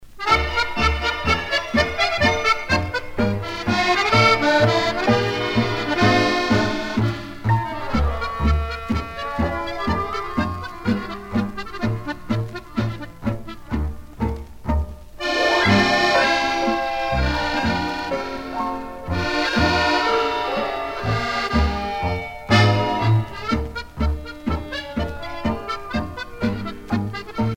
danse : tango musette ;
Pièce musicale éditée